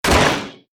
KART_Hitting_Wood_Fence_1.ogg